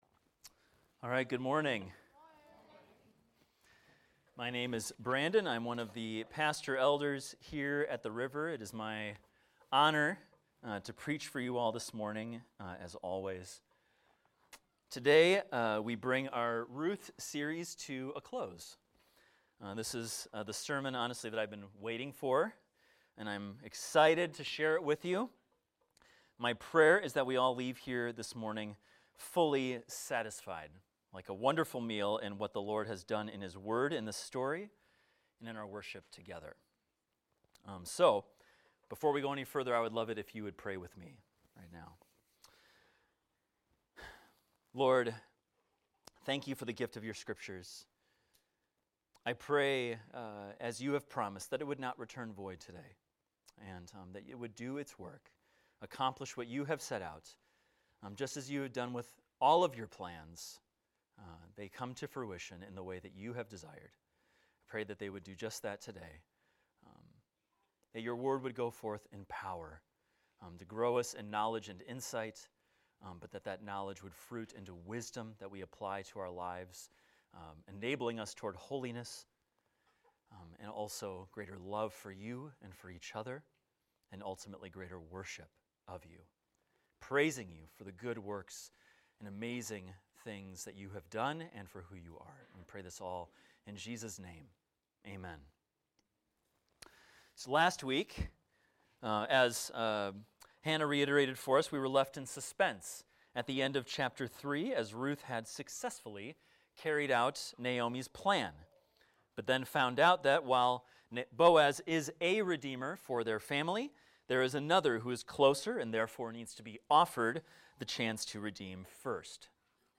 A sermon on Ruth 4 titled "Fully Satisfied"